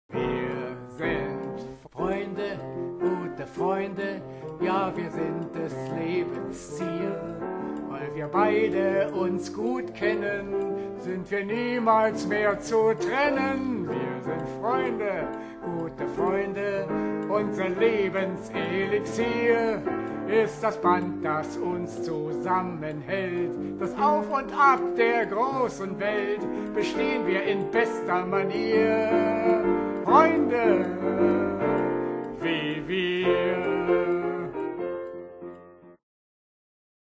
Musical-Komödie für fünf Schauspieler
Klavier und Gesang